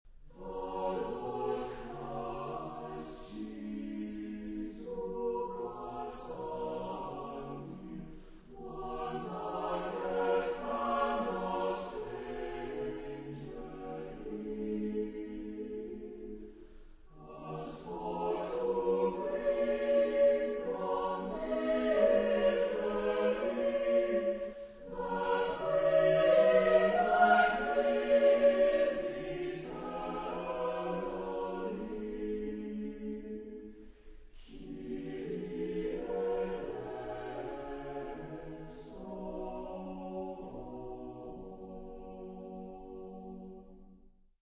Genre-Style-Form: Christmas song ; Sacred ; Oratorio chorus
Type of Choir: SATB  (4 mixed voices )
Tonality: F major